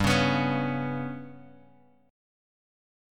GMb5 chord {3 4 x 4 2 x} chord
G-Major Flat 5th-G-3,4,x,4,2,x.m4a